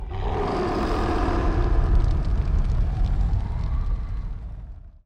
Attachments roar.mp3 roar.mp3 120 KB